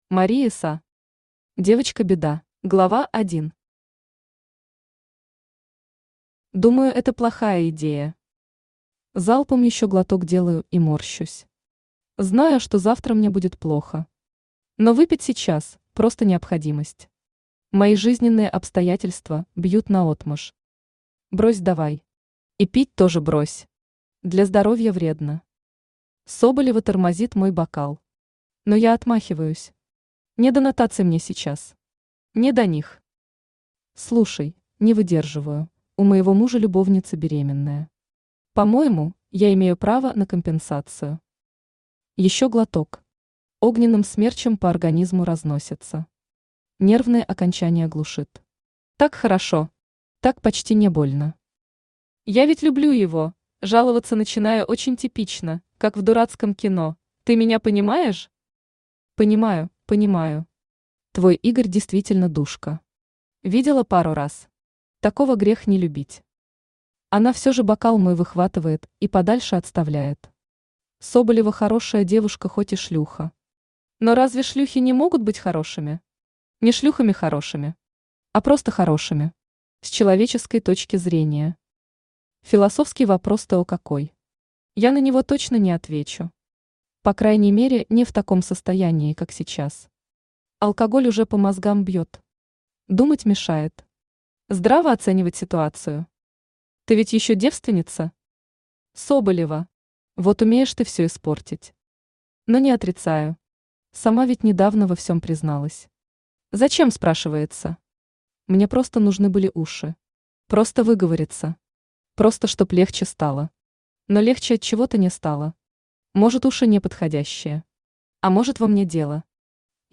Аудиокнига Девочка-беда | Библиотека аудиокниг
Aудиокнига Девочка-беда Автор Мари Са Читает аудиокнигу Авточтец ЛитРес.